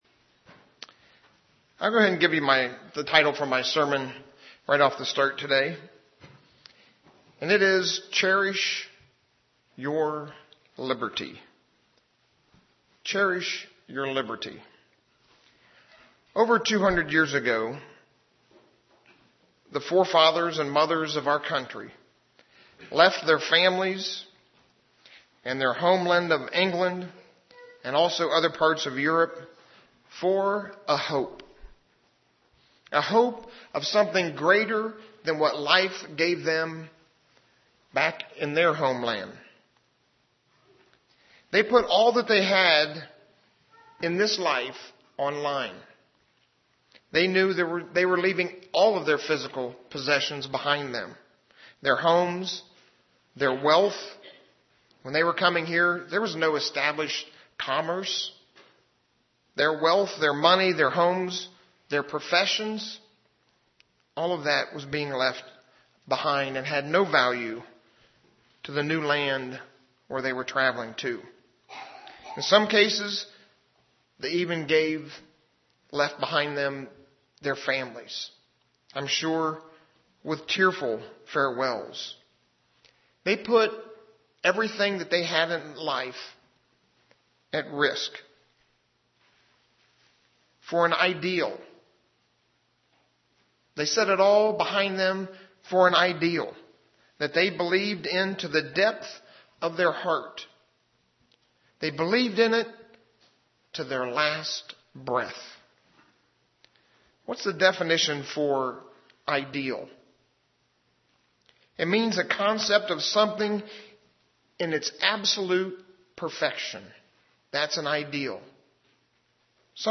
Sermons
Given in Ft. Wayne, IN